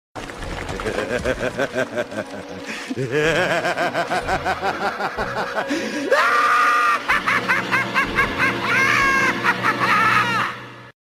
Звуки смеха Джокера
Вы можете слушать онлайн или скачать различные вариации его жуткого, пронзительного и безумного хохота в хорошем качестве.